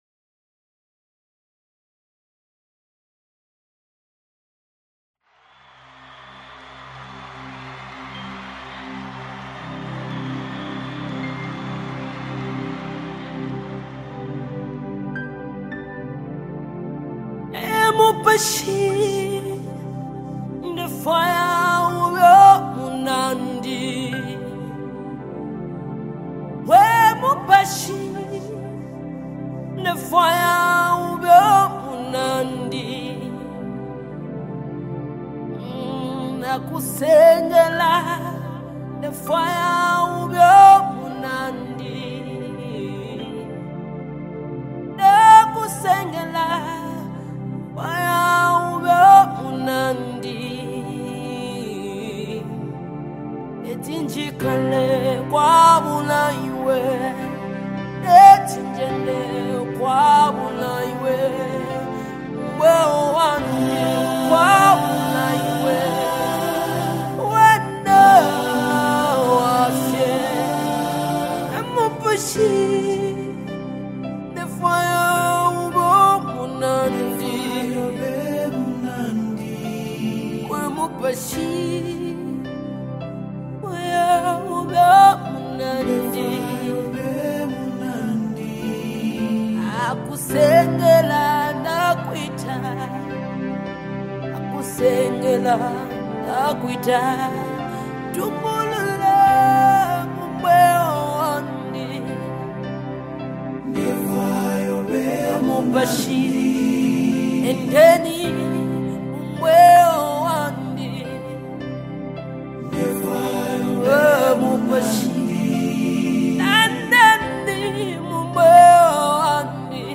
📅 Category: Zambian Deep Worship Song 2025